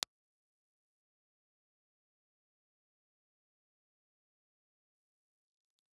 Impulse Response file of the Framez ribbon microphone.
Framez_IR.wav
Wired for balanced, low impedance operation, the sound is clear with a strong output, just lacking a little of the low end proximity boost that you find with many ribbon microphones.